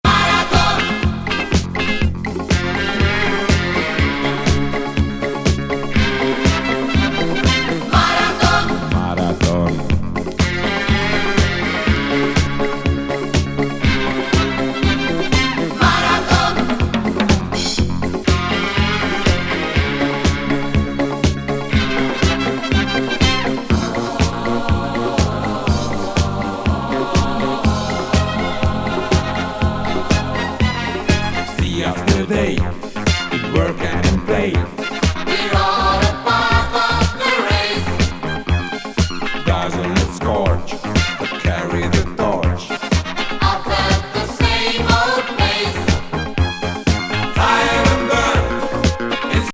ハンガリー出身ポップ・グループ。ユーロ・エレクトロ・ディスコ!